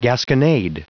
Prononciation du mot gasconade en anglais (fichier audio)
Prononciation du mot : gasconade